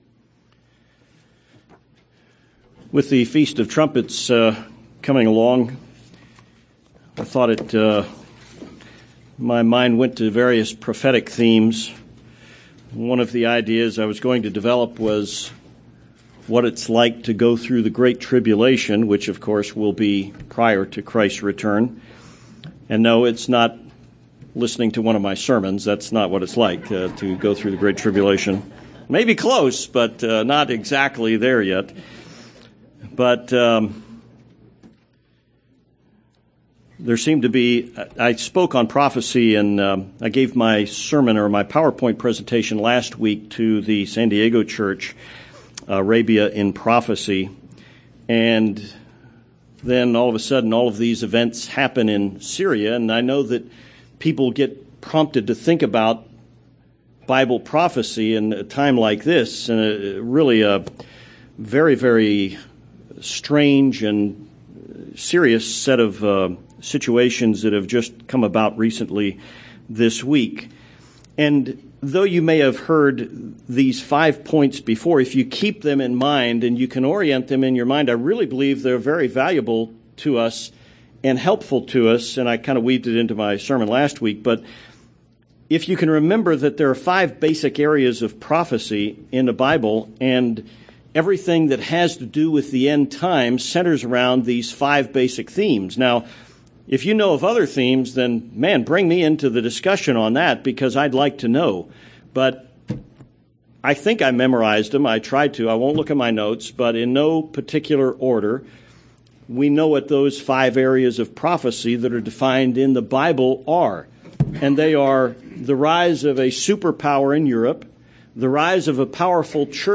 Given in Tucson, AZ
UCG Sermon Studying the bible?